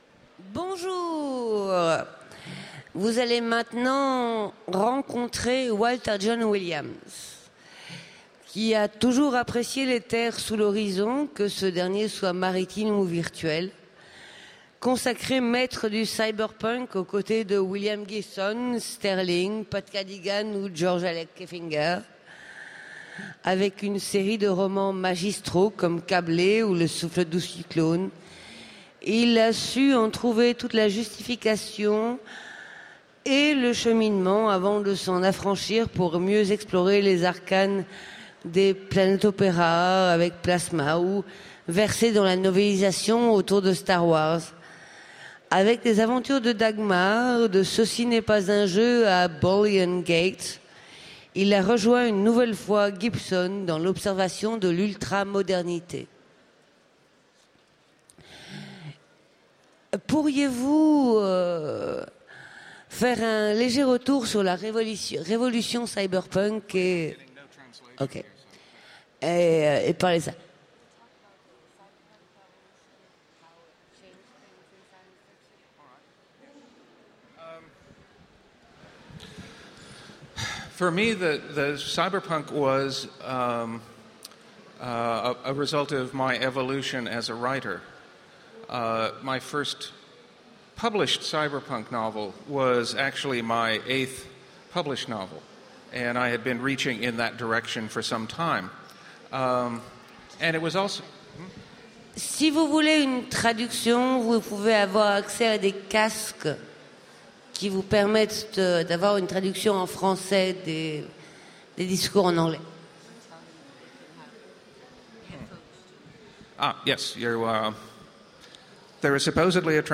Utopiales 2014 : De l’autre côté du cyberpunk : Walter Jon Williams Télécharger le MP3 à lire aussi Walter Jon Williams Genres / Mots-clés Cyberpunk Rencontre avec un auteur Conférence Partager cet article